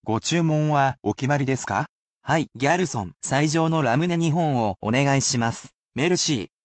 [basic polite + casual speech]